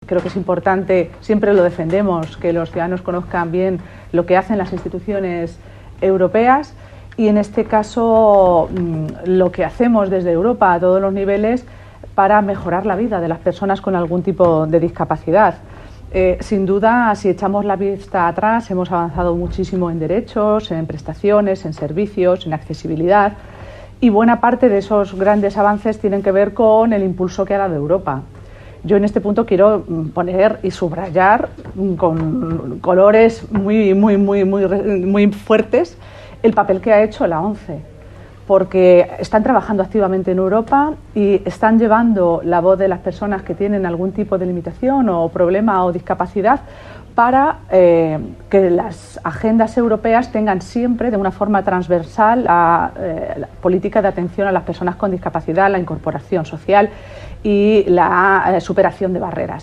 En el mismo intervino la eurodiputada por Castilla-La Mancha, Cristina Maestre, quien señaló que “es muy importante que los ciudadanos conozcan lo que hacemos desde las instituciones europeas para mejorar la vida de las personas con algún tipo de discapacidad”.
CorteCristinaMaestre.mp3